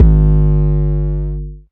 DDK1 808 5.wav